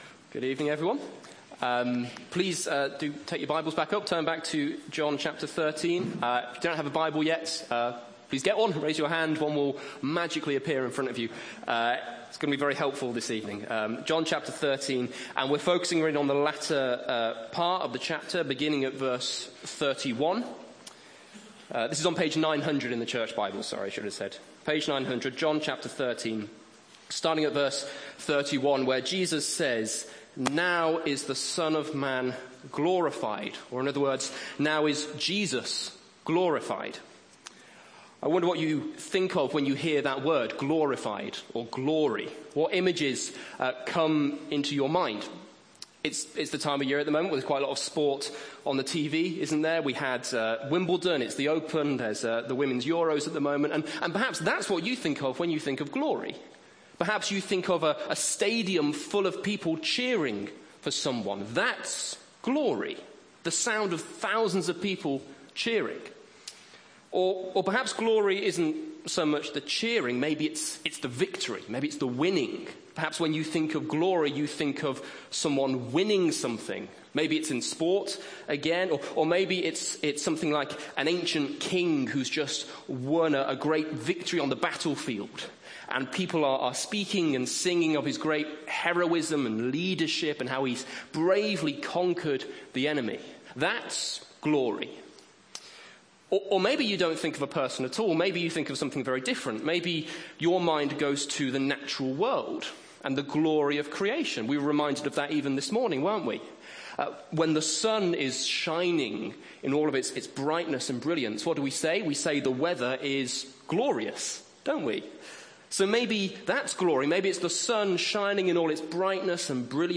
PM Sunday Evening Service